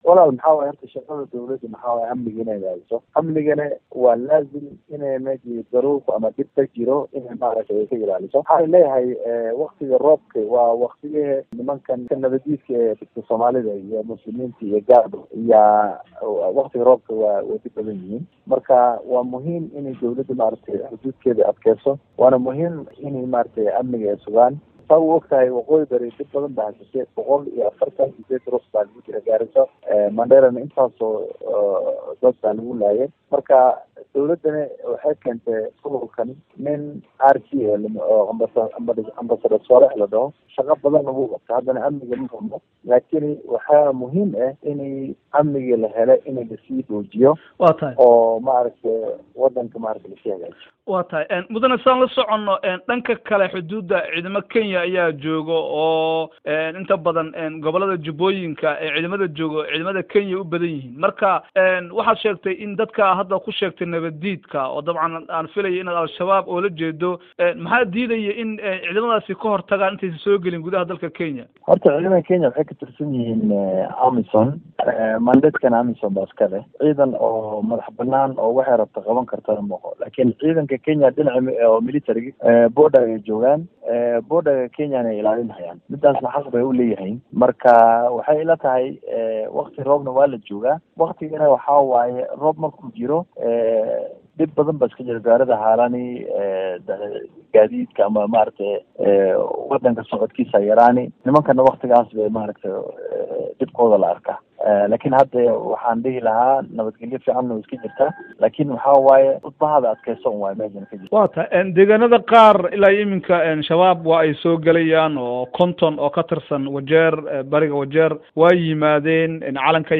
Wareysi: Xildhibaan Shidiye